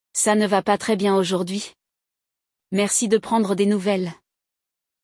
No episódio de hoje, vamos acompanhar dois amigos conversando pelo telefone. Um deles não se sente muito bem, e o outro está ligando para oferecer seu apoio.